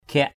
/kʱiaʔ/ (t.) cháy = brûlée. be burned. lasei khiak ls] A`K cơm cháy, cơm khét. khiak juk A`K j~K cháy đen.